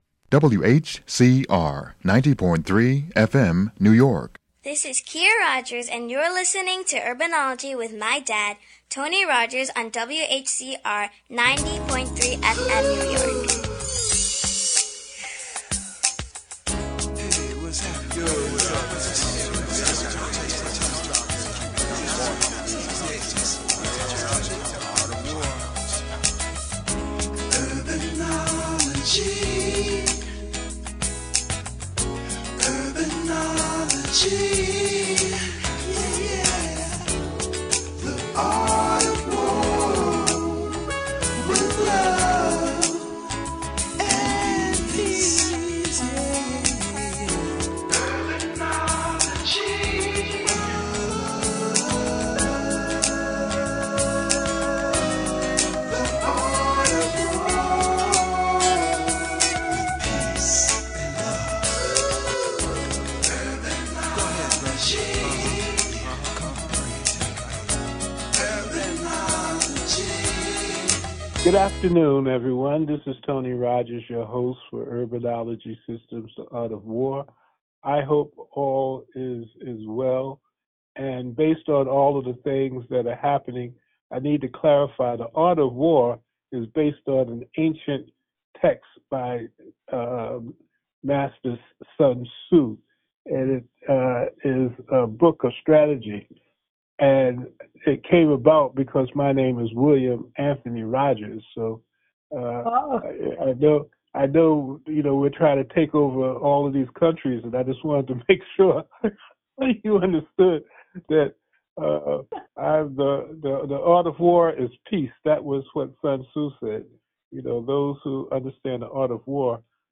Urbanology WHCR 90.3 FM Interview